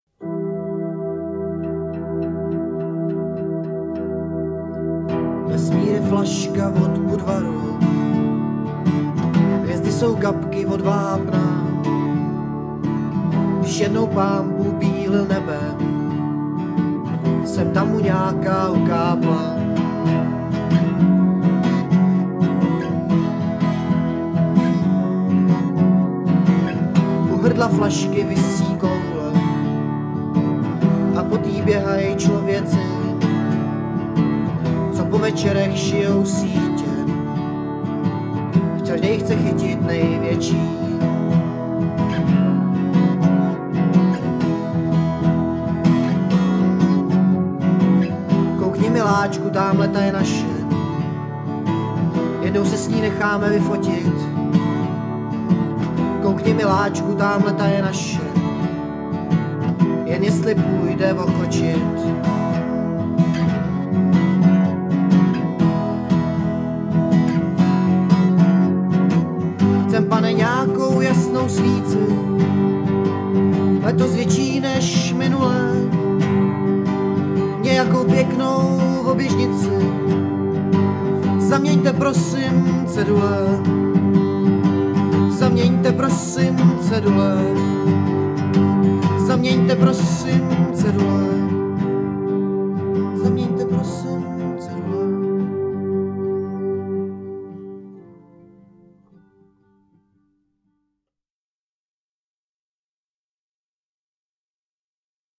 kbd, harm, dr
g, sitar, harm